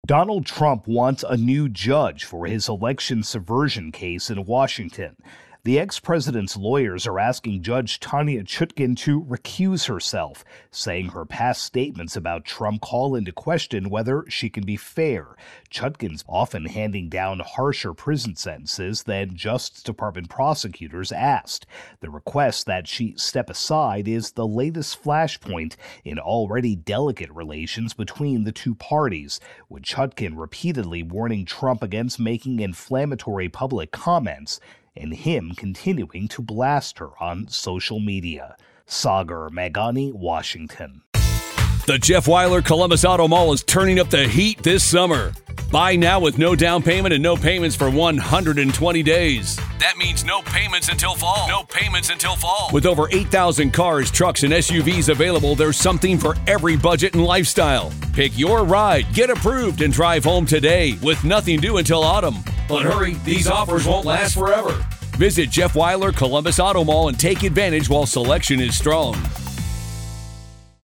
AP Washington correspondent